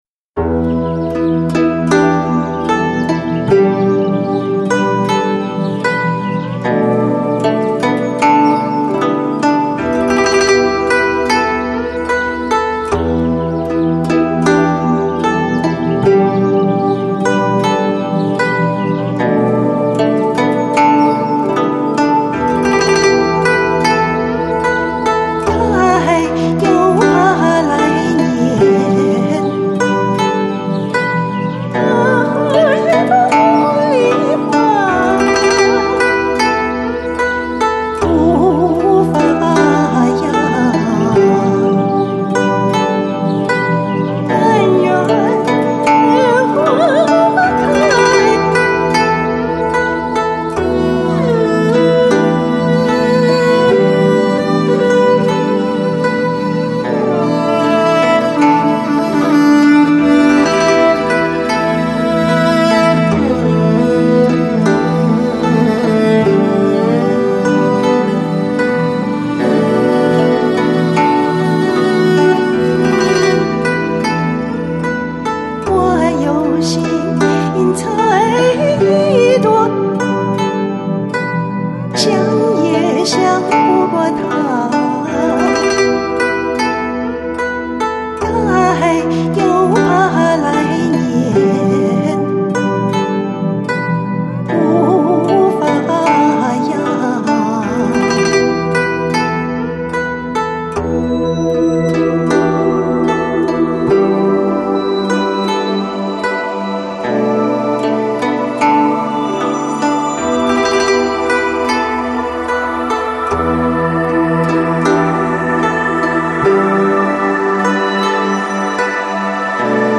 Lounge, Chill Out, Downtempo Издание